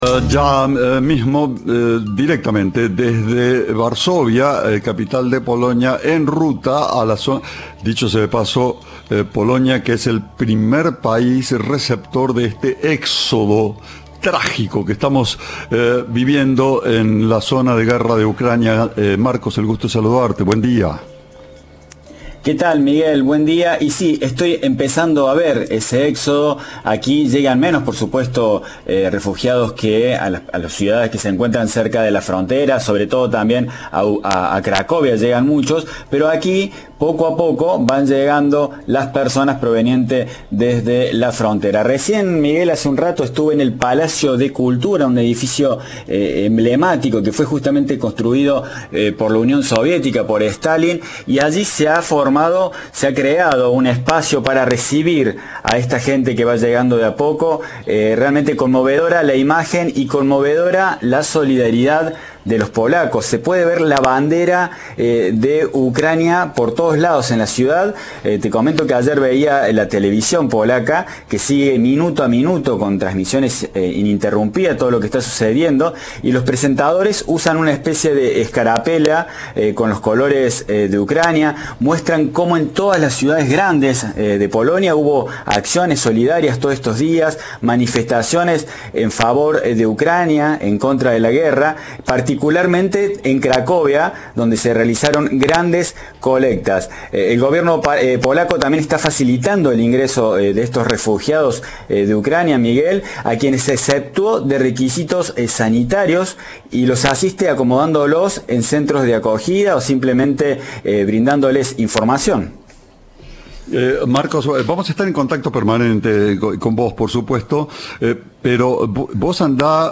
habló con Cadena 3 de la conmovedora la solidaridad de los polacos hacia los refugiados en el Palacio de Cultura de Varsovia.